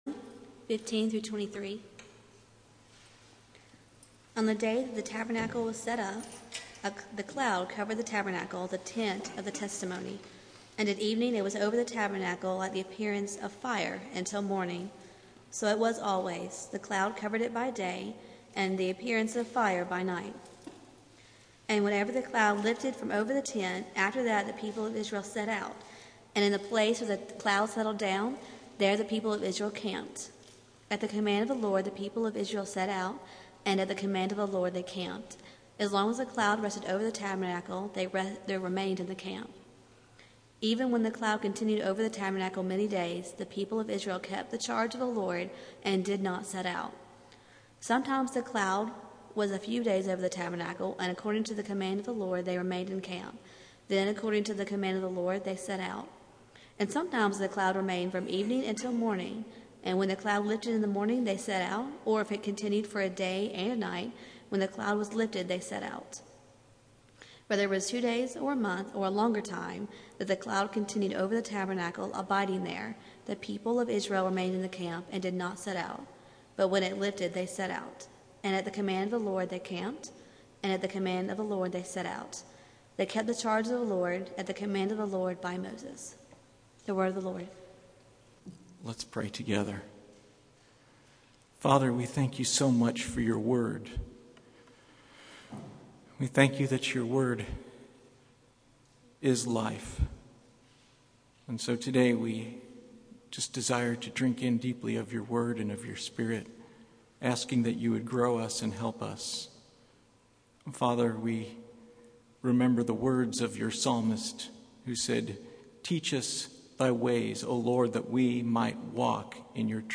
Preacher
Service Type: Sunday Morning